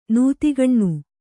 ♪ nūtigaṇṇu